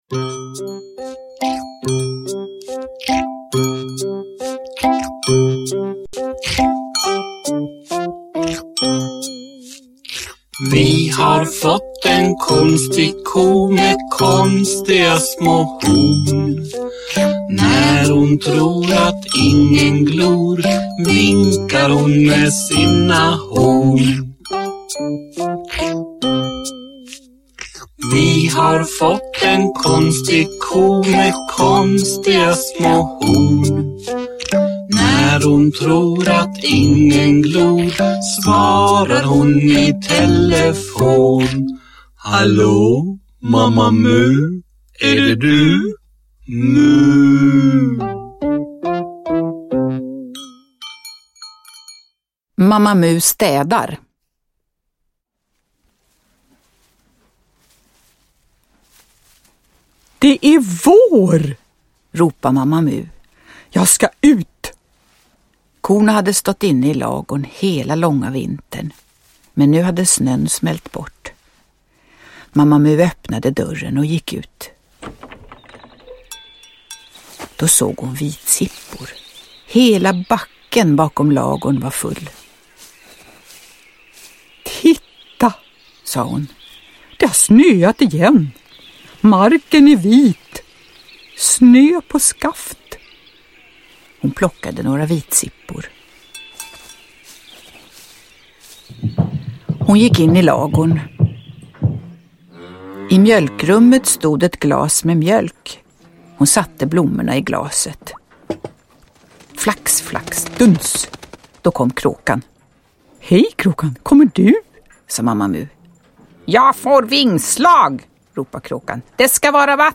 Mamma Mu städar – Ljudbok – Laddas ner
Uppläsning med musik.
Uppläsare: Jujja Wieslander